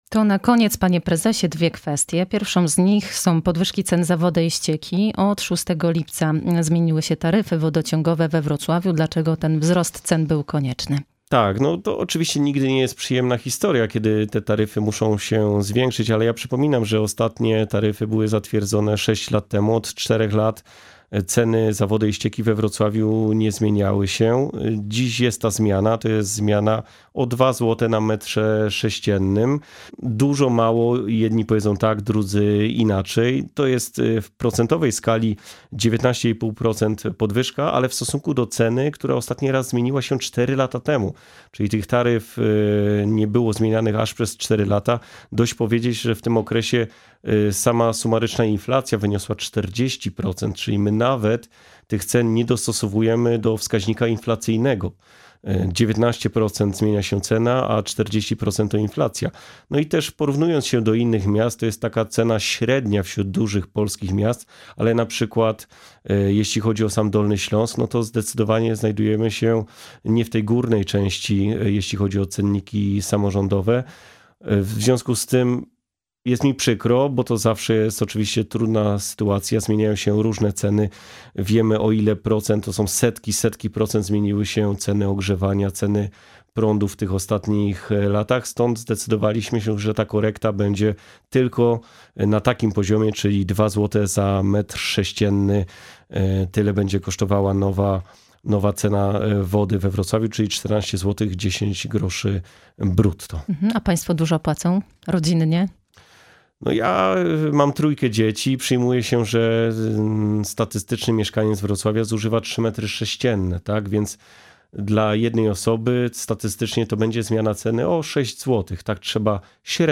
Pytamy w wywiadzie.